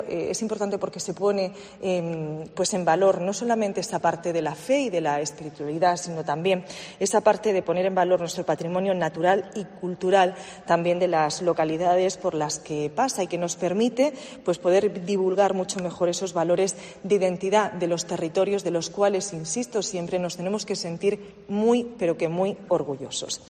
Carmen Conesa, Consejera de Turismo